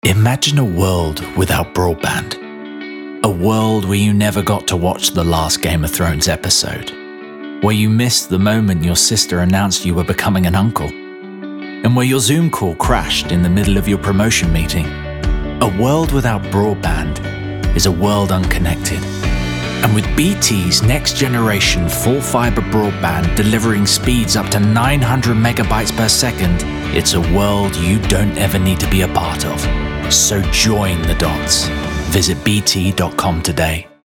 Voice Reel
Conversational, Engaging, Warm